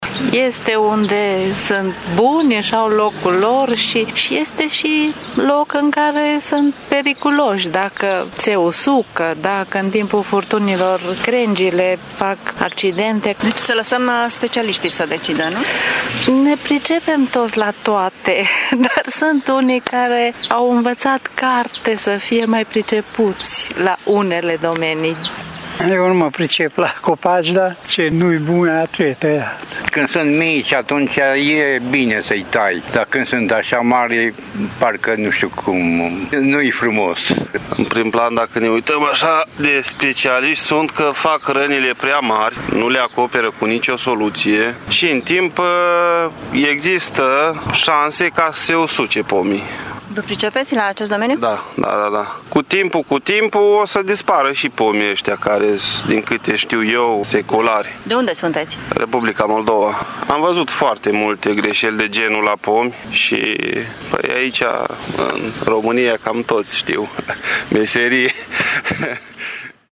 Cele mai multe comentarii de acest fel apar în mediul on-line, dar părerile târgumureșenilor sunt împărțite cu privire la toaletările efectuate de Primărie: